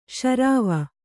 ♪ śarāva